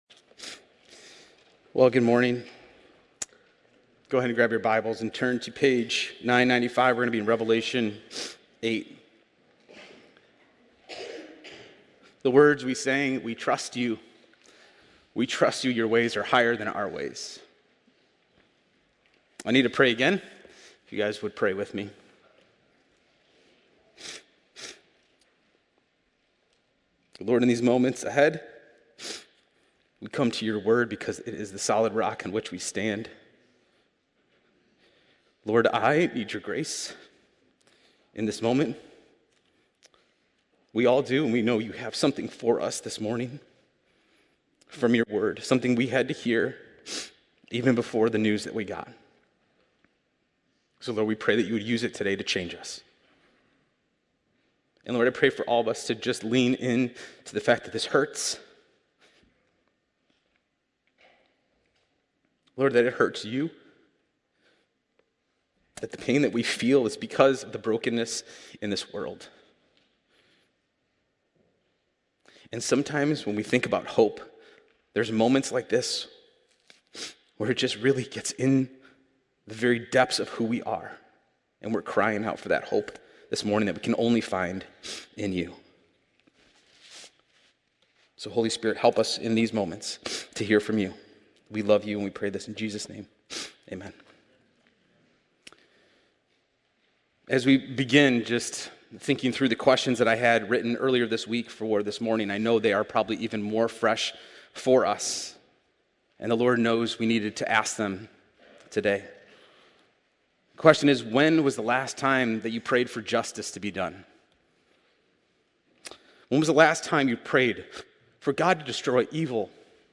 Podcast (cityview-sermons): Play in new window | Download (Duration: 41:18 — 18.9MB) | Embed